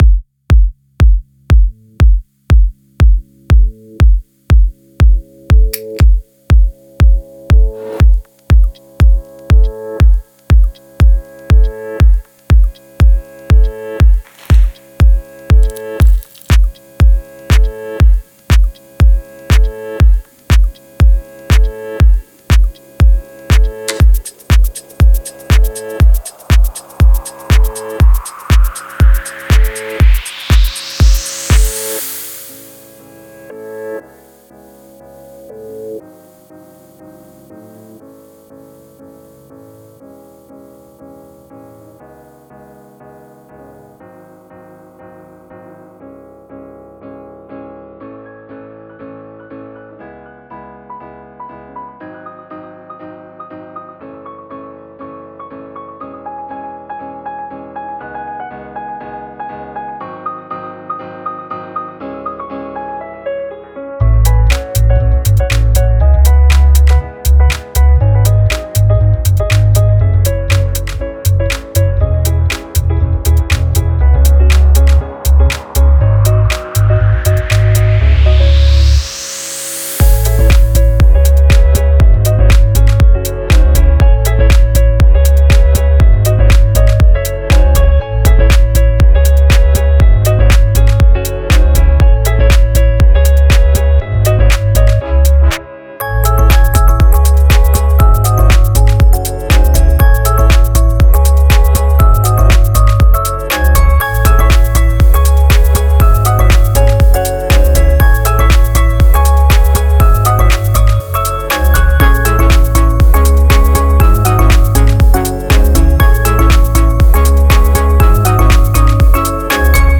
прогрессивного house